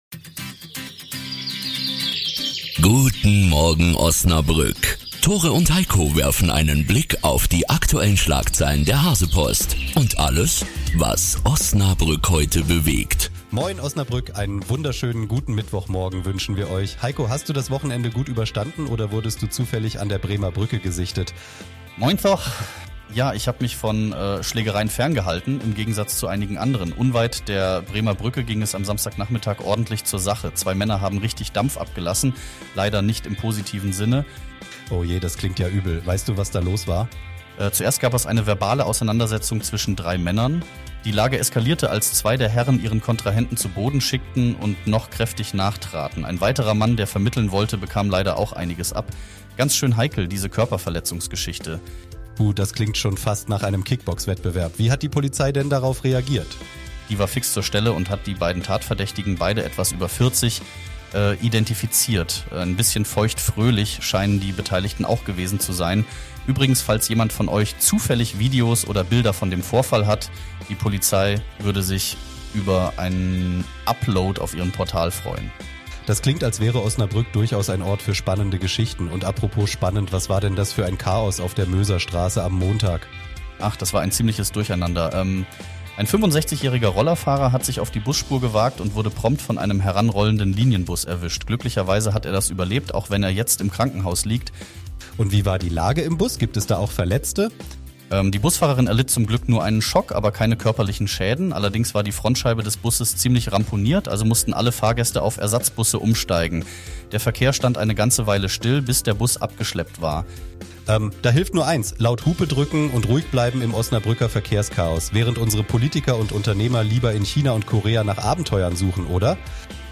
Die aktuellen Schlagzeilen der HASEPOST aus Osnabrück.